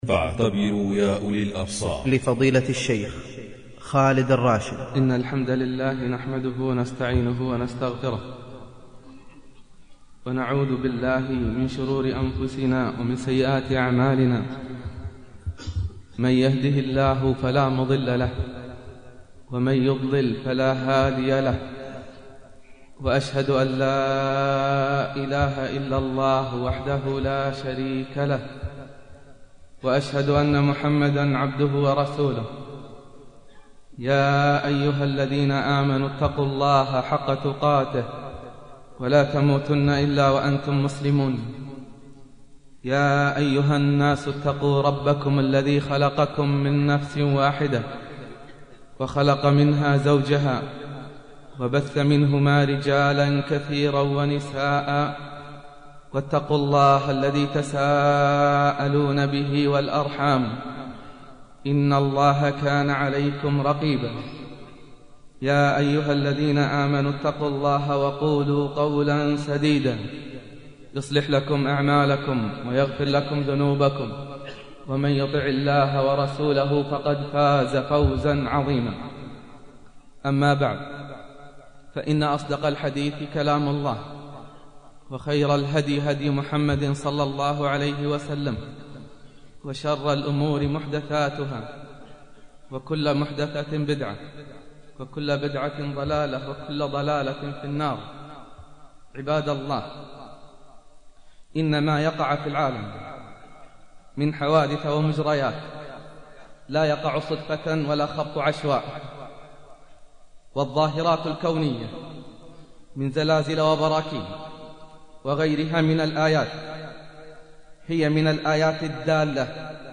خطبة الحاجة والتنبيه افتتح الخطيب بحمد الله والثناء عليه، والتحذير من البدع والمعاصي، والتأكيد أن ما يحدث في الكون ليس صدفة، بل بأمر الله وقدره.
الخاتمة والدعاء اختتم الخطيب بدعاء مؤثر، سأل فيه الله أن يرحم المسلمين، ويغفر ذنوبهم، ويصلح أحوالهم، وينصر المجاهدين، ويدفع البلاء عن الأمة.